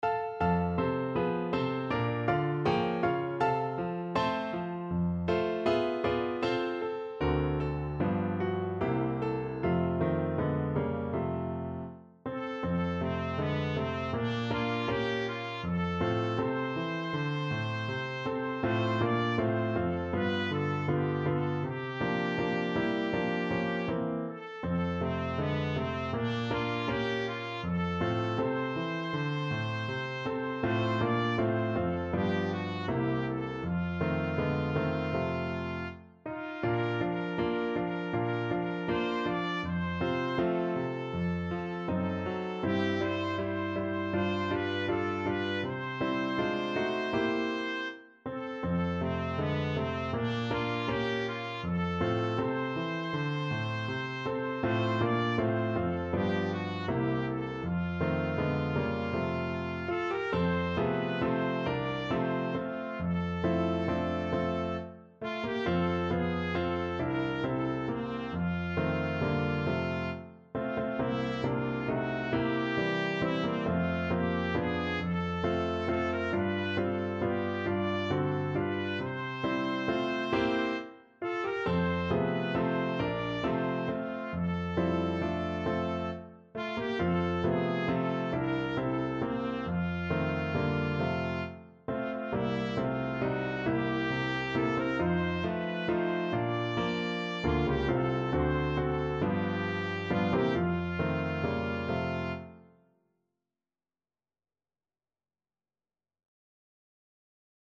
Trumpet
F major (Sounding Pitch) G major (Trumpet in Bb) (View more F major Music for Trumpet )
4/4 (View more 4/4 Music)
Andante
Pop (View more Pop Trumpet Music)